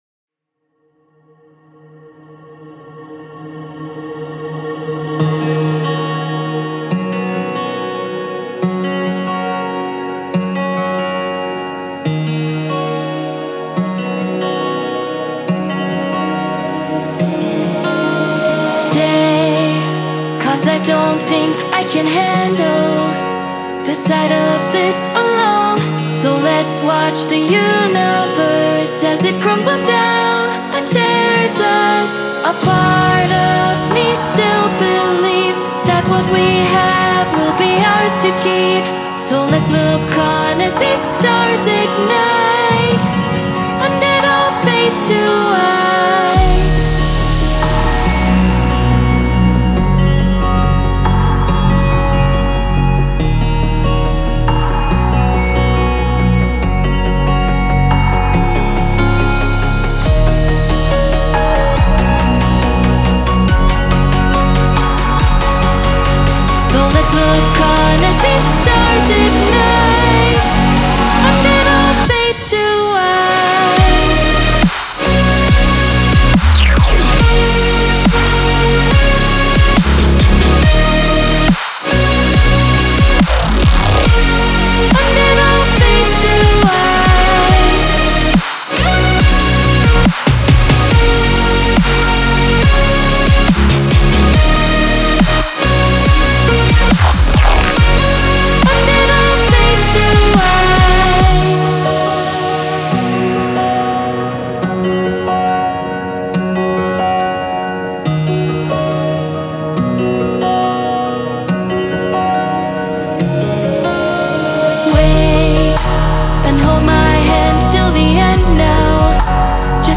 new vocalist??